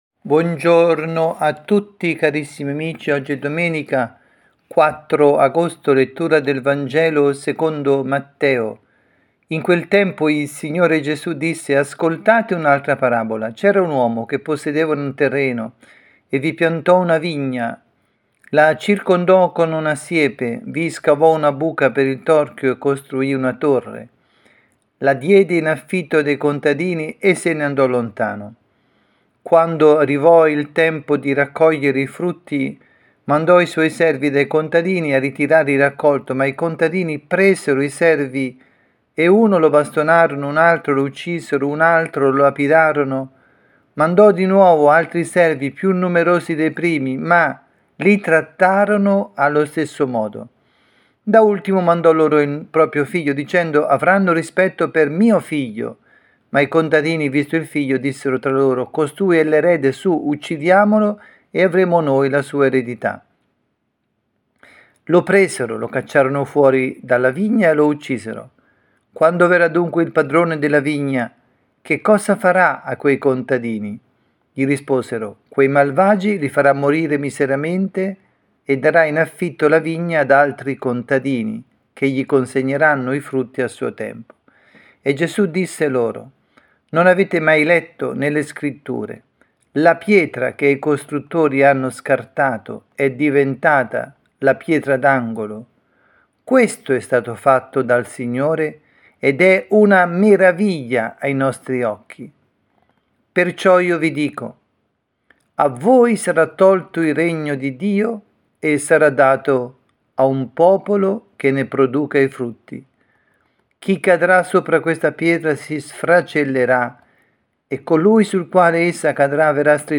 Catechesi
dalla Parrocchia Santa Rita – Milano –  Vangelo del rito Ambrosiano –  Matteo 21, 33-46.